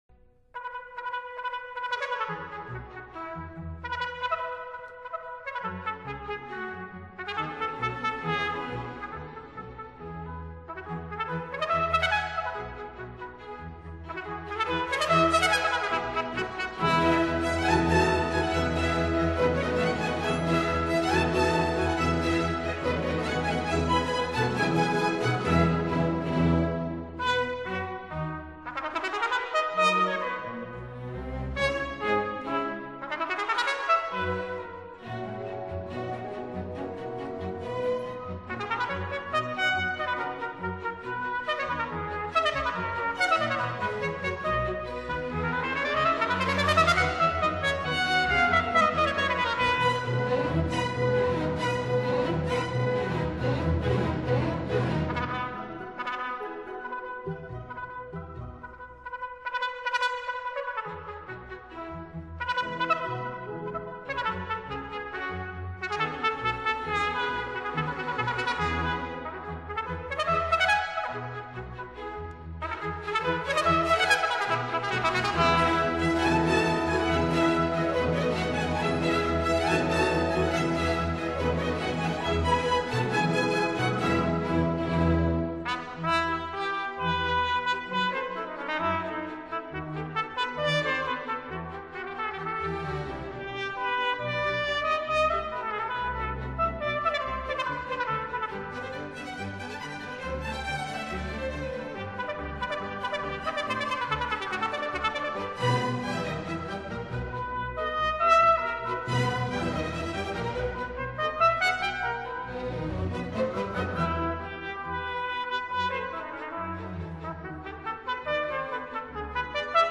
精彩的小號協奏經典曲
Trumpet
Rondo(64K/WMA